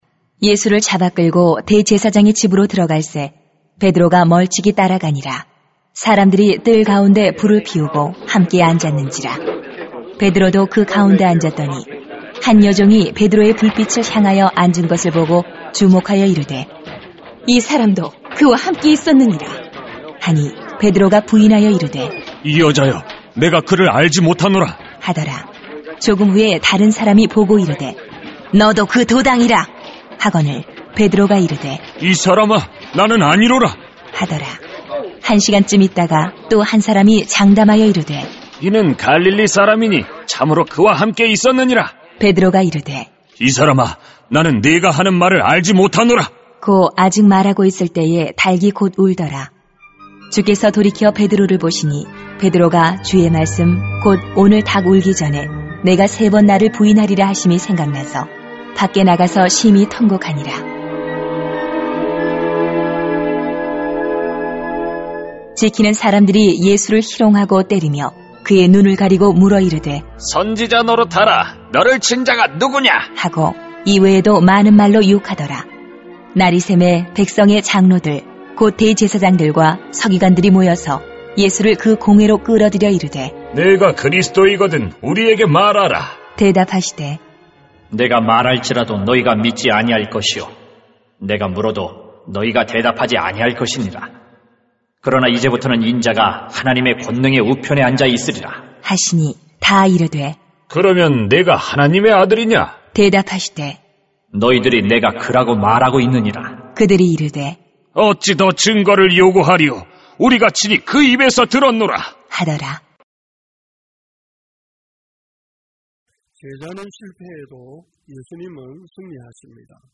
[눅 22:54-71] 제자는 실패해도 예수님은 승리하십니다 > 새벽기도회 | 전주제자교회